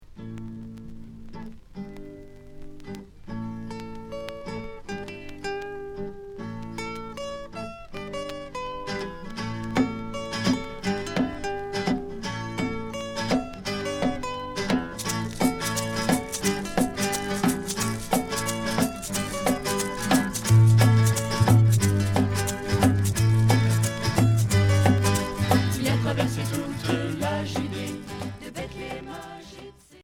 Folk religieux